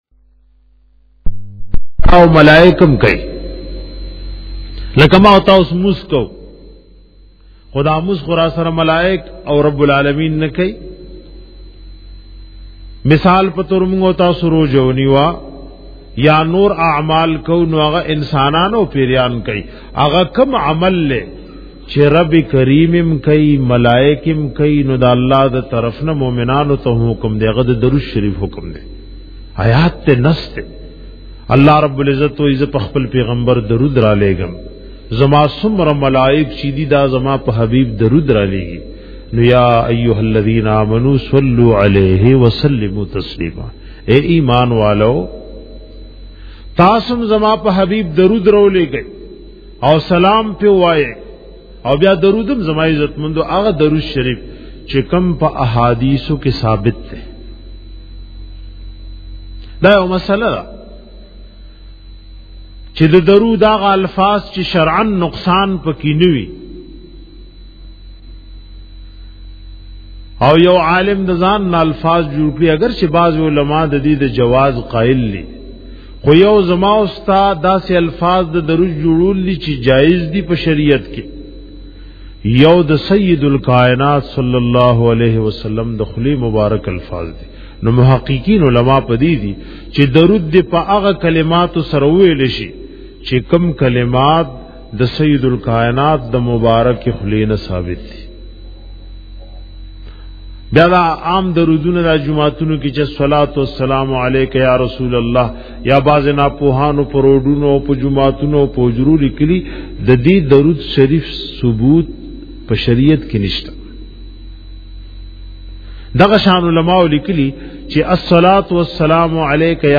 bayan pa bara da fazilat da darood sarif